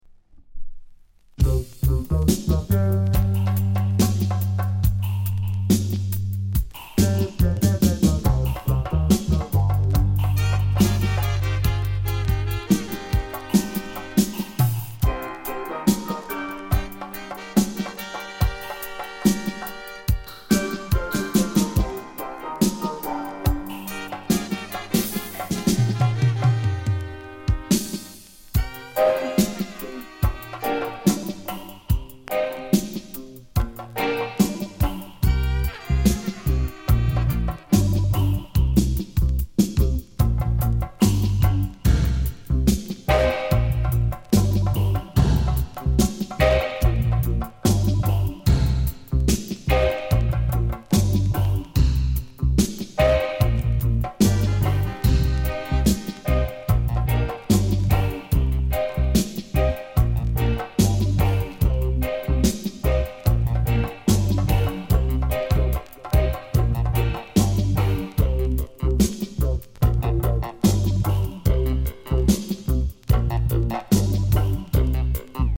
UK・英 7inch/45s